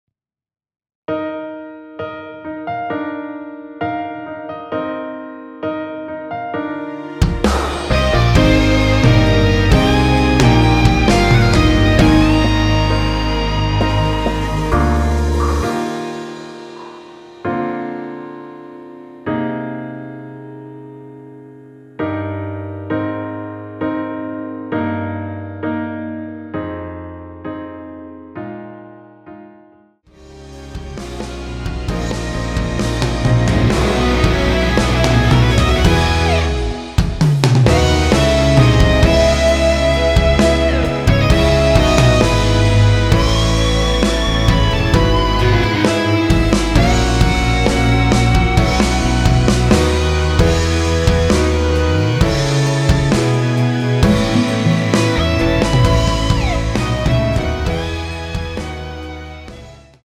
원키에서(+2)올린 MR입니다.
Eb
앞부분30초, 뒷부분30초씩 편집해서 올려 드리고 있습니다.
중간에 음이 끈어지고 다시 나오는 이유는